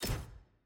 sfx-jfe-ui-generic-click.ogg